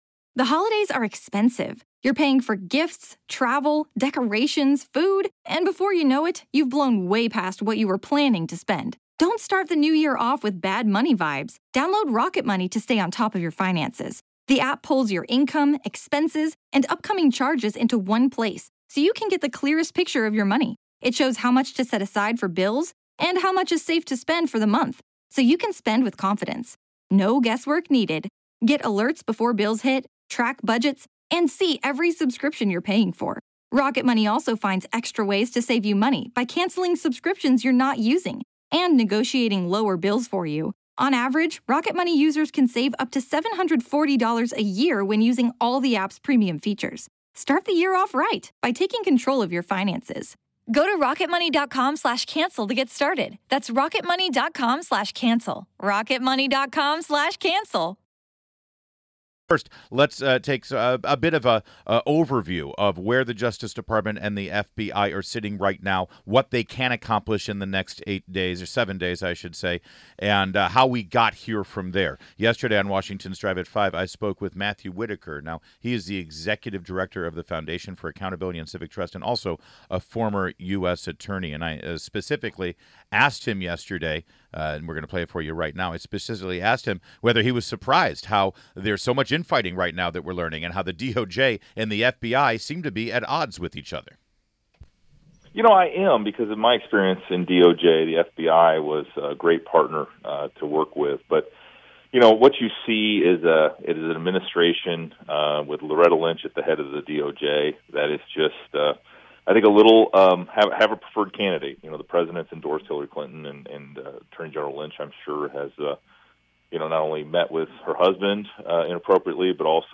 WMAL Interview - MATTHEW WHITAKER - 11.01.16